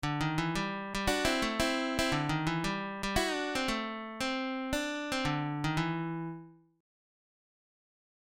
Blues lick > lick 10